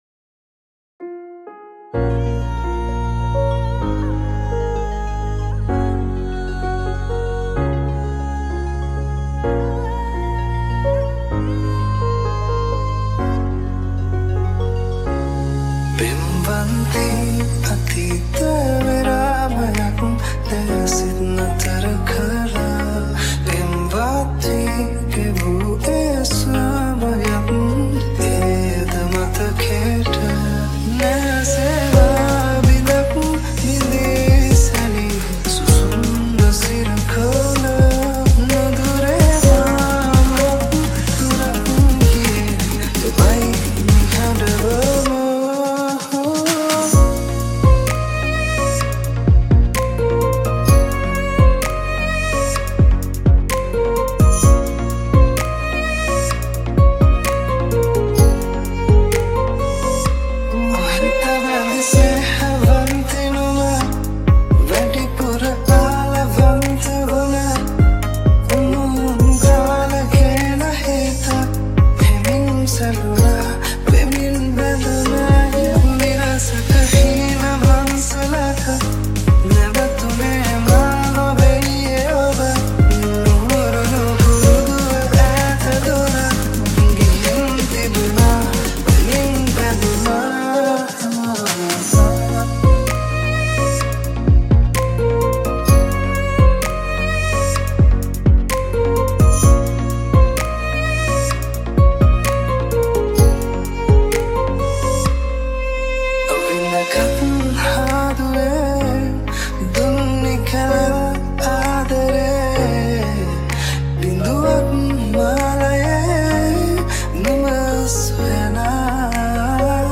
Remix Version